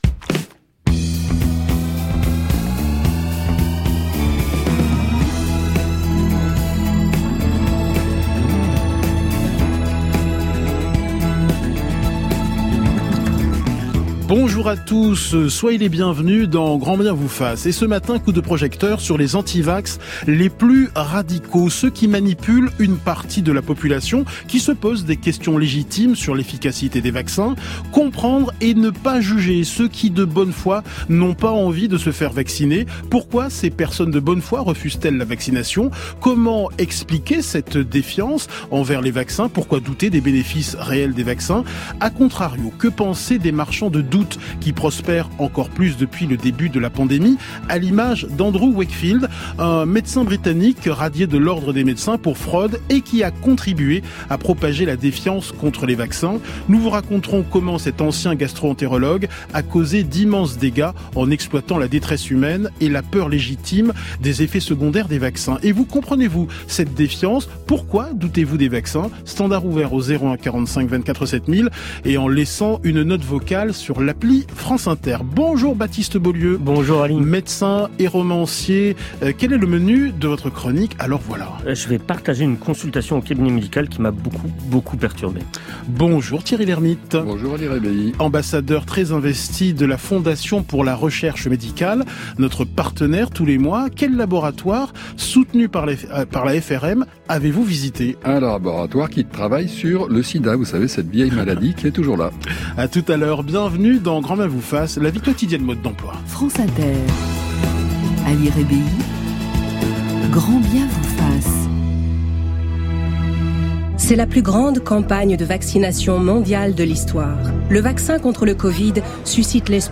Lundi 13 décembre 2021, l’émission sur France-Inter « GRAND BIEN VOUS FASSE ! » qui est animée par Ali Rebeihi a pour but de déterminer le profil des « antivax radicaux ».